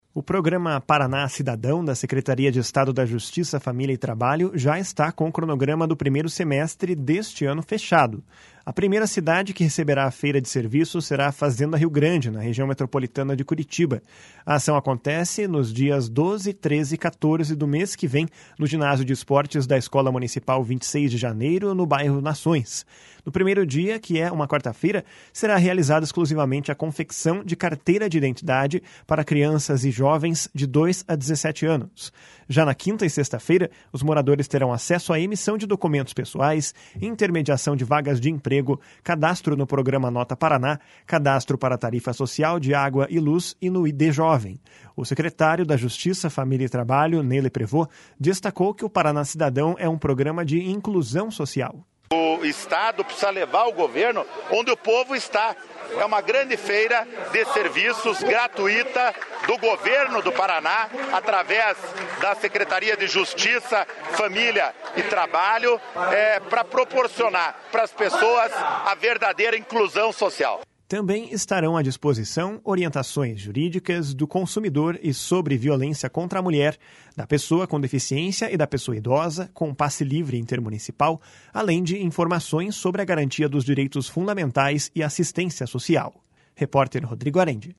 O secretário da Justiça, Família e Trabalho, Ney Leprevost, destacou que o Paraná Cidadão é um programa de inclusão social. // SONORA NEY LEPREVOST //